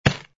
fs_ml_stone01.wav